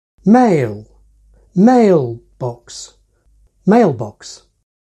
In a compound like mailbox, for instance, where box is deaccented, mail gets the accent as if it were the end of the phrase.